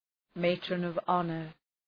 Shkrimi fonetik{,meıtrənəv’ɒnər}